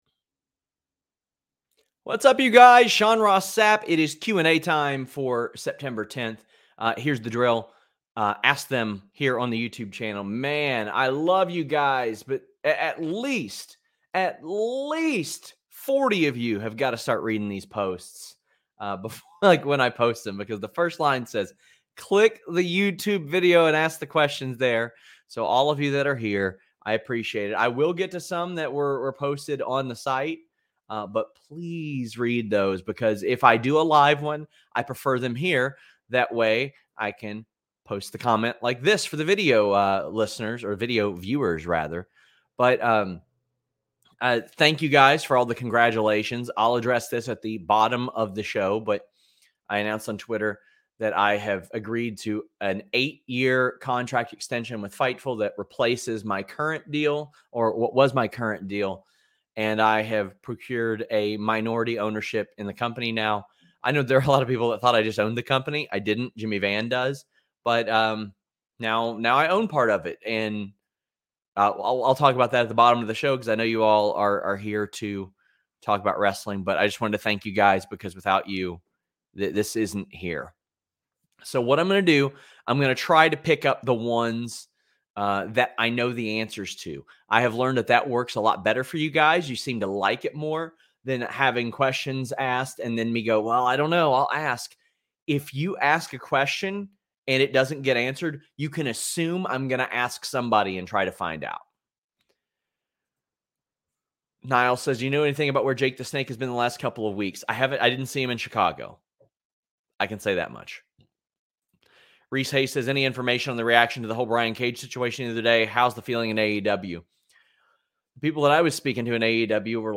"SRS LIVE Q&A at 12:15 EST!" by fightful from Patreon | Kemono